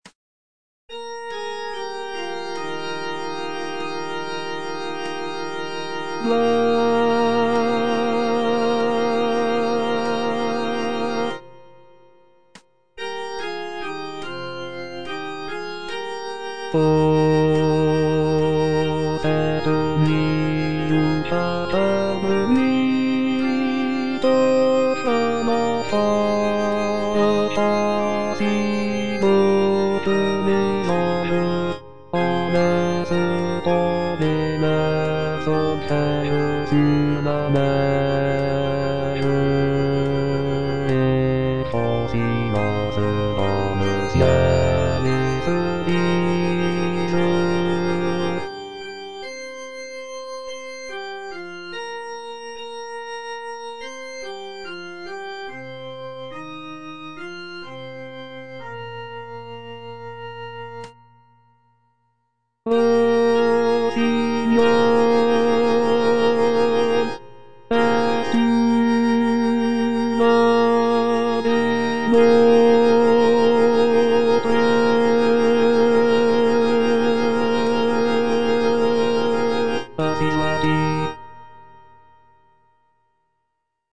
Bass (Voice with metronome)